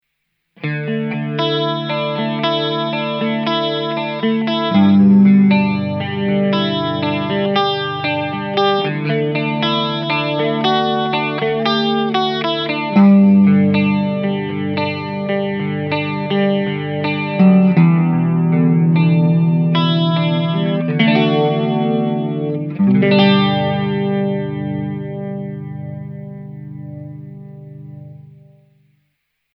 All audio clips are recorded with a Marshall JCM900 amp set with a clean tone.
Guitar: Fender Stratocaster (neck and middle pickup)
Mode: Ensemble
Speed: 4/10
Depth: 5/10
• Ensemble is based on the characteristics of Boss world, combining the circuitry and liquid character of the CE-1 with the versatility and subtleness of the CE-2, generating a typically 80s character;